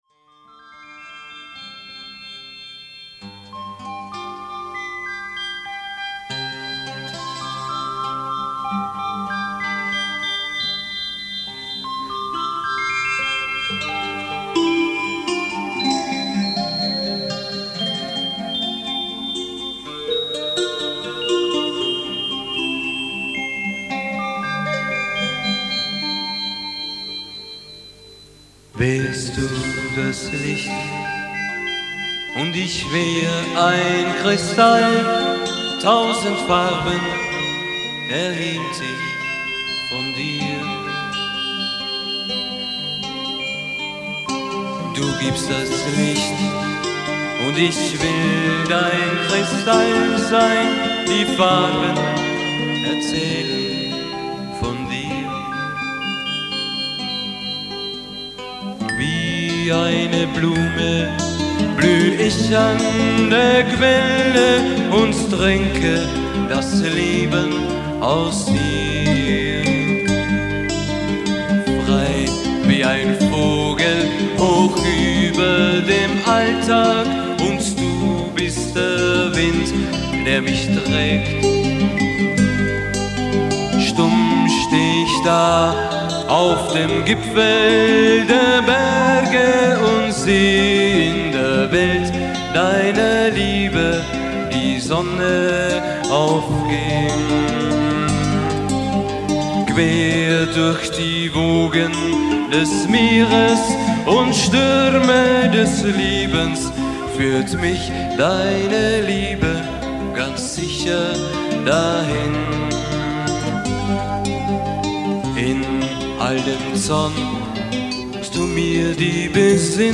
Lieder mit Chorsätzen